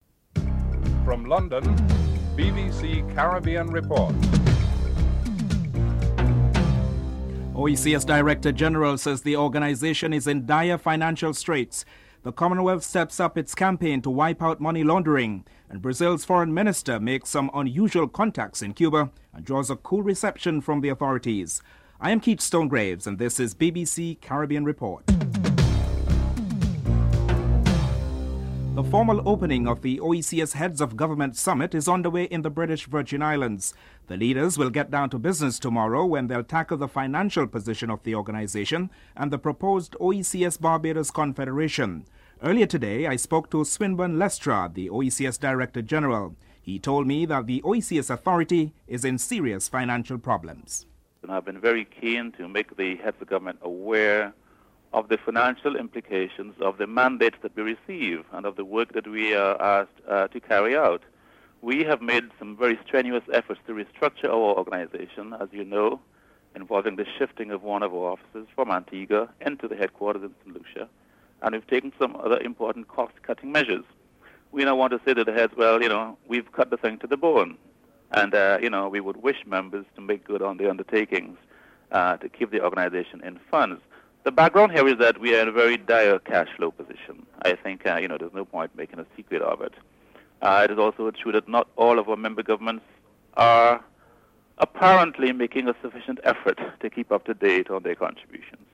1. Headlines (00:00-00:28)